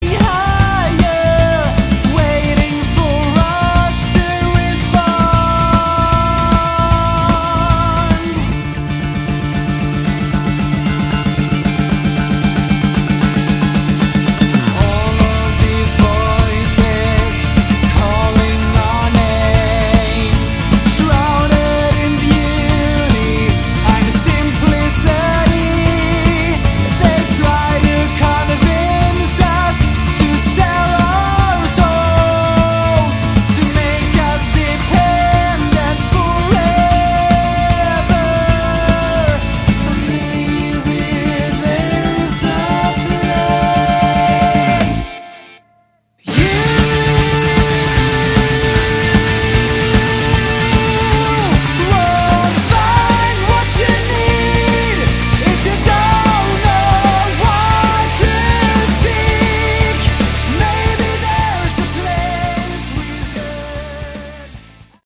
Category: Prog/Hard Rock
lead vocals, bass
guitars
drums